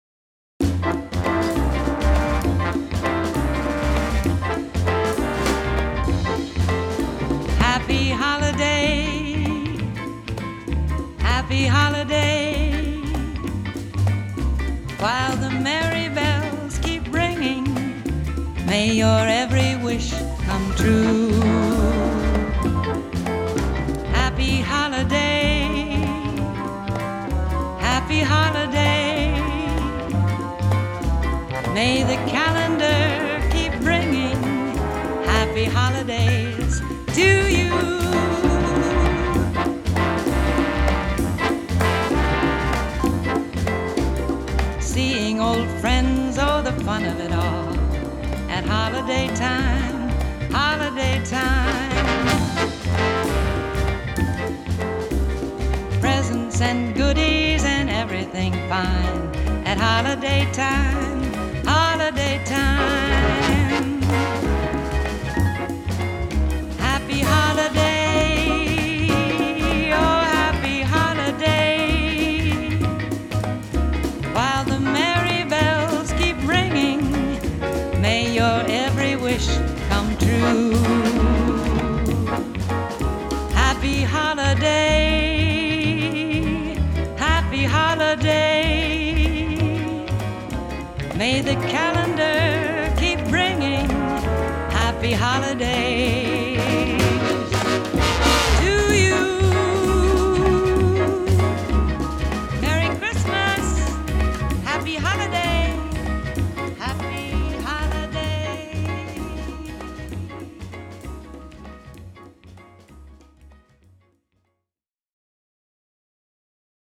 a swinging rendition
Jumping into Christmas in swingin’ fashion.
lending a sultry tone to the festivities.